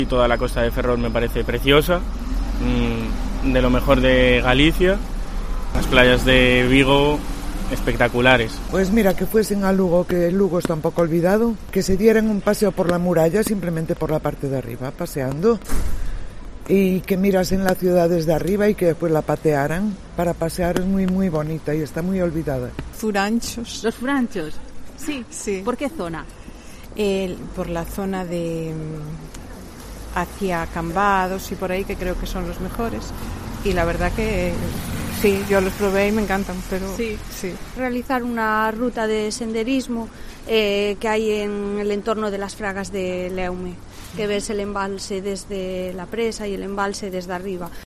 Lo comprobamos en la calle y otra gallega recomienda un paseo por la parte superior de la muralla romana de Lugo.
"Los mejores son los de la zona de Cambados", recomienda en los micrófonos de Cope una vecina.